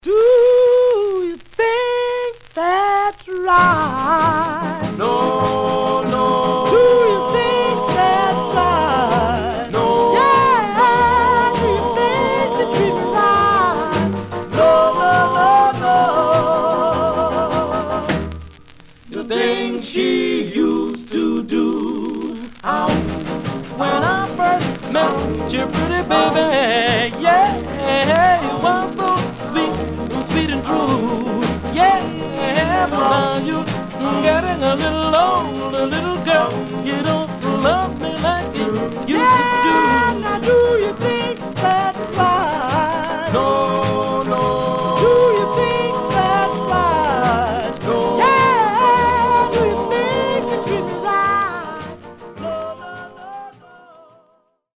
Surface noise/wear
Mono
Male Black Groups